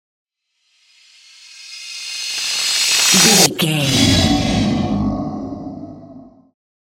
Creature dramatic riser
Sound Effects
In-crescendo
Atonal
ominous
eerie
riser
roar